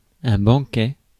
Ääntäminen
IPA: [bɑ̃.kɛ]